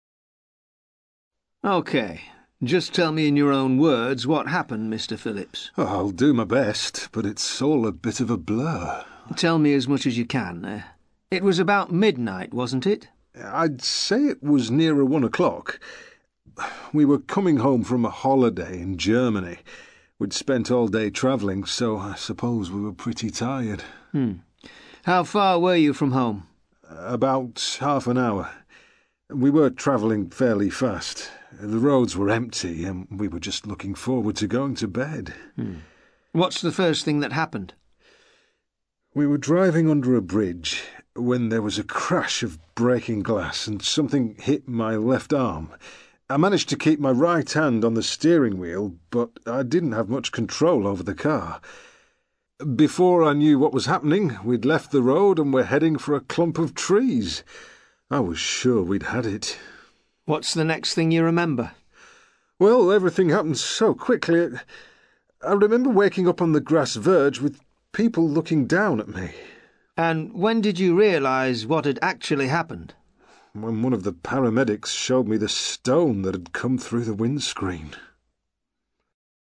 ACTIVITY 130: You are going to hear part of an interview between a police officer and a motorist who has been involved in a driving incident.